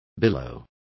Also find out how oleada is pronounced correctly.